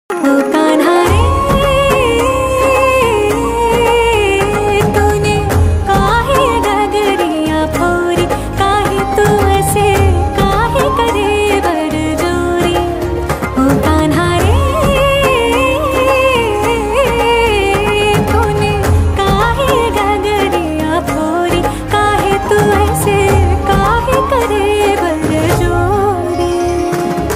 A Melodious Fusion
• Simple and Lofi sound
• Crisp and clear sound